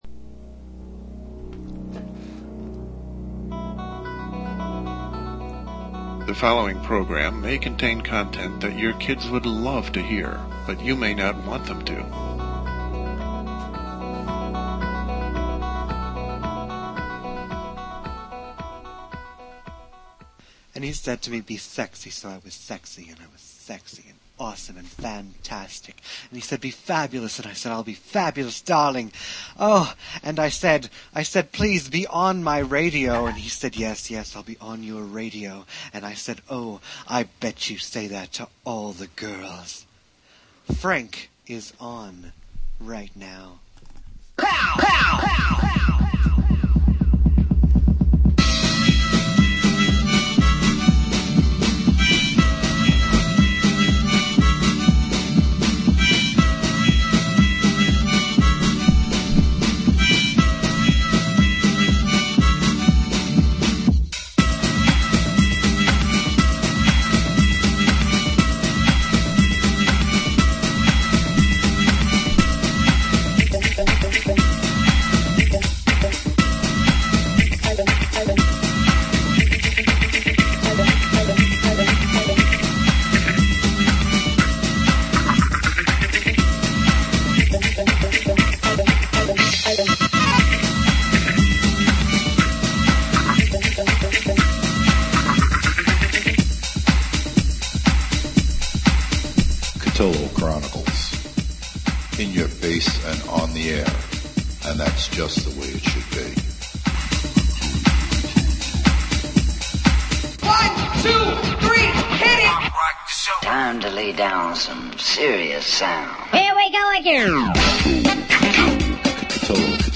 Portions of classic shows that people may have missed, since our audience has grown by leaps and bounds since 2005, will be discussed and aired. With and without guests, check out this gallery of exclusive broadcast material from our archives, some the most popular over the past eight years. It happens live.